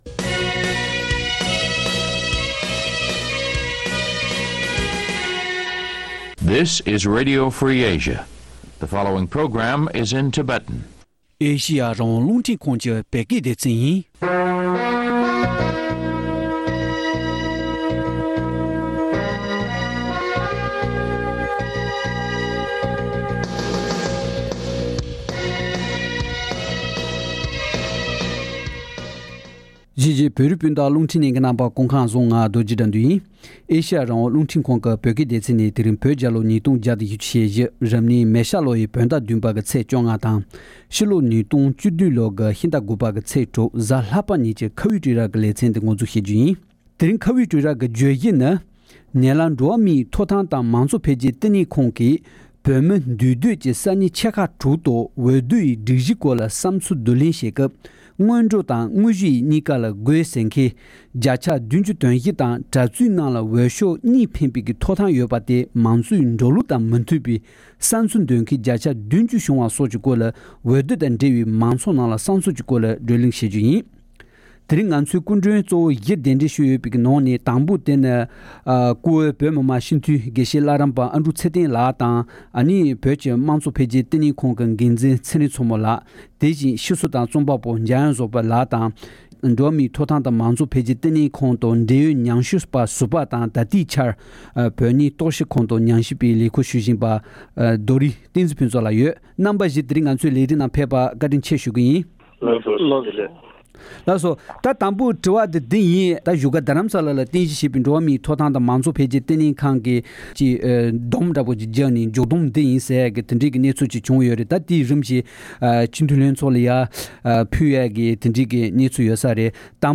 ཐེངས་འདིའི་ཁ་བའི་གྲོས་ར་ཞེས་པའི་ལེ་ཚན་གྱི་ནང་དུ་ཉེ་ལམ་འགྲོ་བ་མིའི་ཐོབ་ཐང་དང་དམངས་གཙོ་འཕེལ་རྒྱས་ལྟེ་གནས་ཁང་གིས་བོད་མི་འདུས་སྡོད་ཀྱི་ས་གནས་ཆེ་ཁག་དྲུག་ཏུ་འོས་བསྡུའི་སྒྲིག་གཞིའི་སྐོར་ལ་བསམ་ཚུལ་བསྡུ་ལེན་བྱེད་སྐབས་སྔོན་འགྲོ་དང་དངོས་གཞི་གཉིས་ཀ་དགོས་ཟེར་མཁན་བརྒྱ་ཆ་ ༧༤ དང་། གྲྭ་བཙུན་རྣམས་ལ་འོས་ཤོག་གཉིས་འཕེན་པའི་ཐོབ་ཐང་ཡོད་པ་དེ་དམངས་གཙོའི་འགྲོ་ལུགས་དང་མི་མཐུན་པའི་བསམ་ཚུལ་འདོན་མཁན་བརྒྱ་ཆ་ ༧༠ བྱུང་བ་སོགས་ཀྱི་སྐོར་འོས་བསྡུར་དང་འབྲེལ་བའི་མང་ཚོགས་ནང་བསམ་ཚུལ་ཀྱི་སྐོར་གླེང་མོལ་བྱས་པར་ཉན་རོགས༎